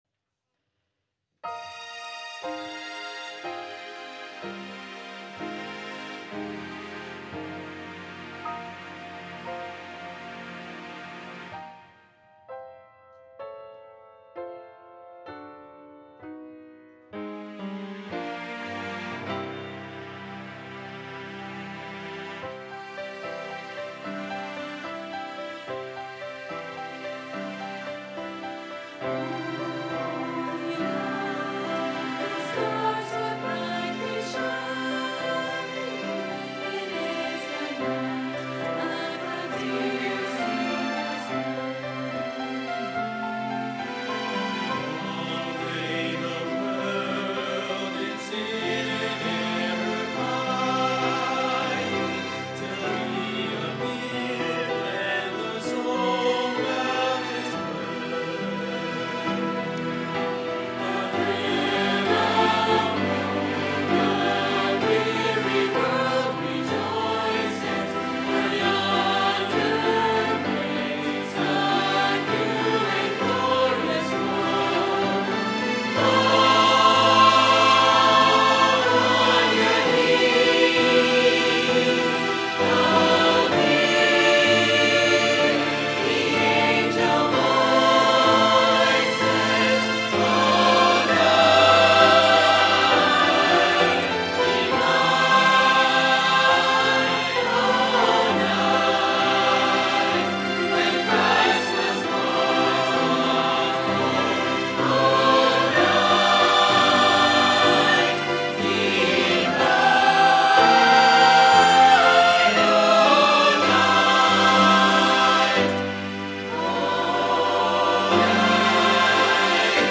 “O Holy Night” VBC Choir (Christmas 2024) | Banned But Not Bound
by VBC Choir | Verity Baptist Church
Choir-2.mp3